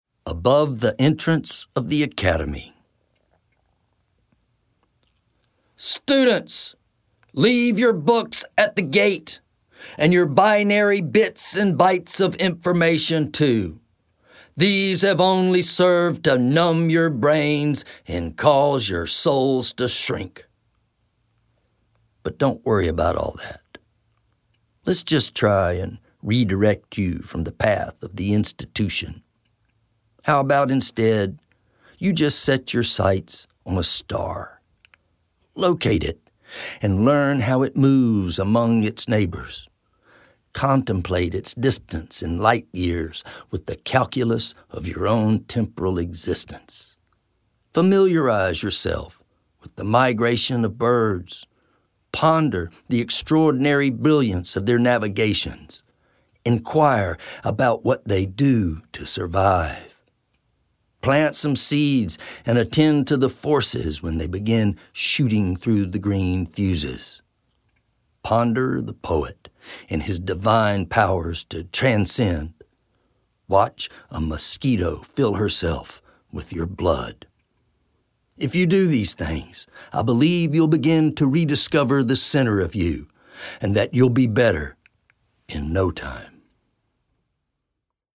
Spoken Poems